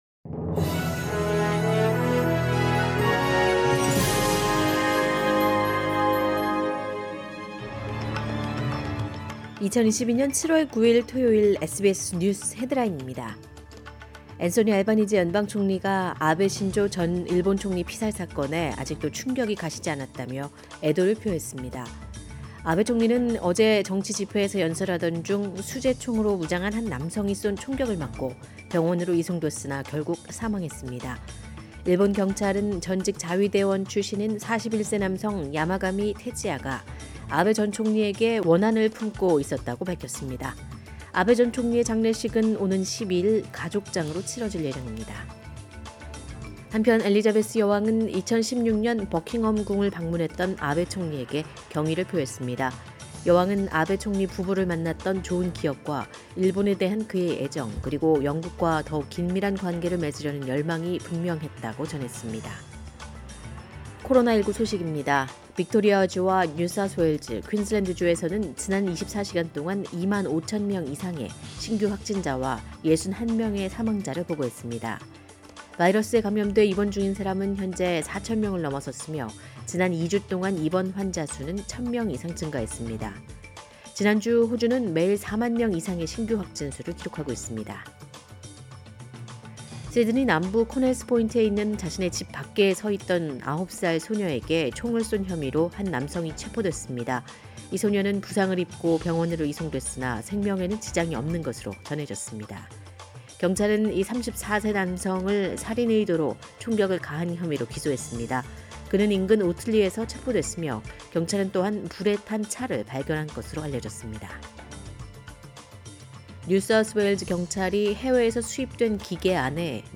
2022년 7월 9일 토요일 SBS 한국어 간추린 주요 뉴스입니다.